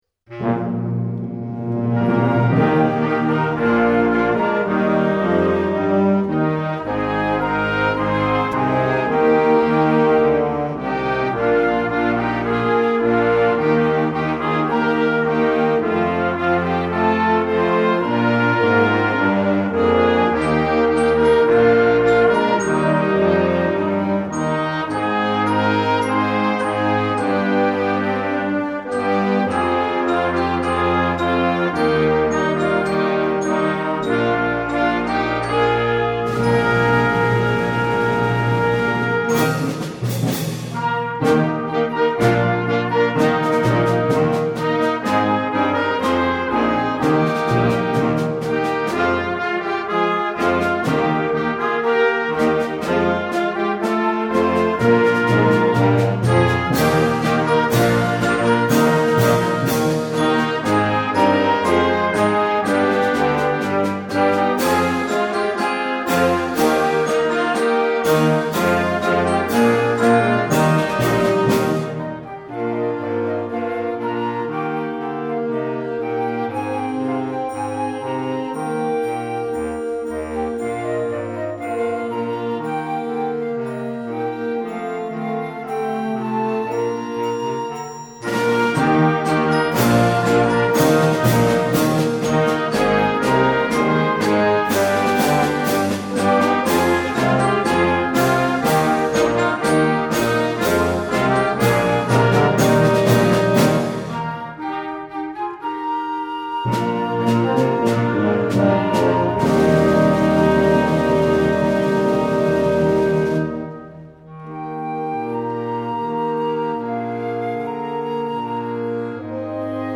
for Winds and Percussion